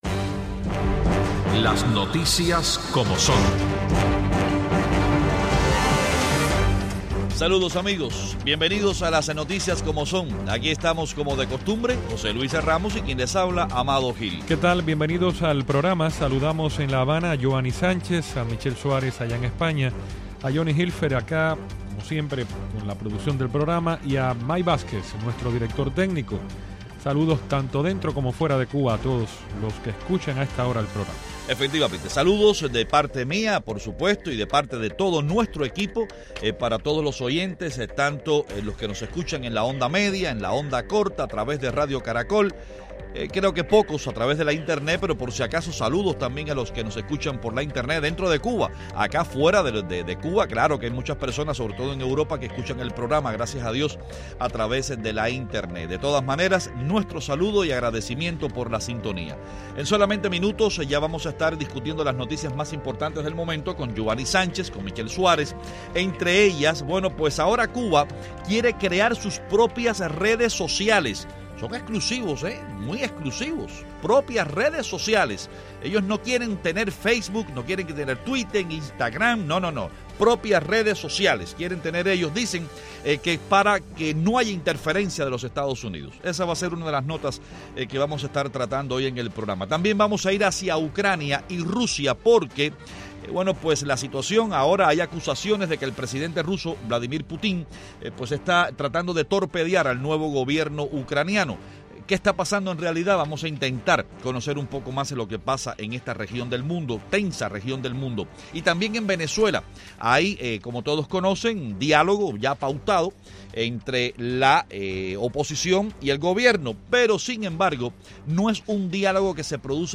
Los periodistas cubanos Yoani Sánchez, desde La Habana